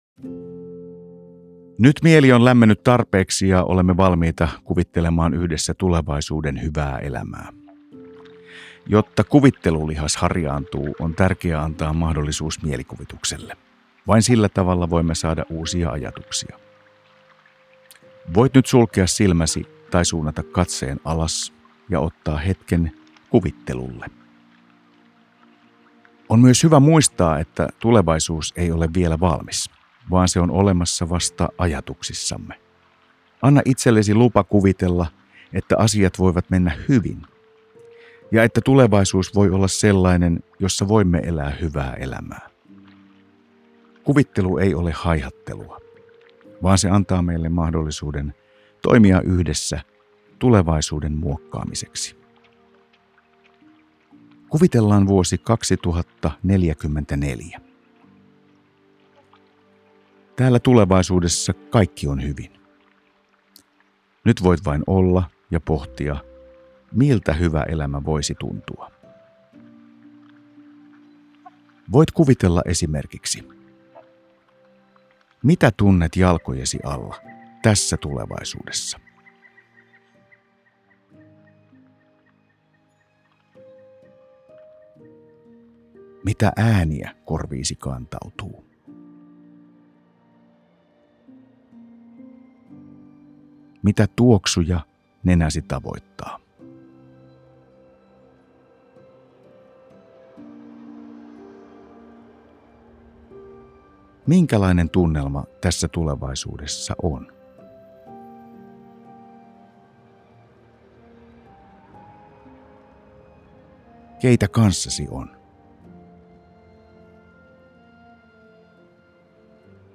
Äänite on helppo tapa kuljettaa harjoitusta ja se sisältää äänitehosteita kuvittelun helpottamiseksi ja sopivan tunnelman luomiseksi.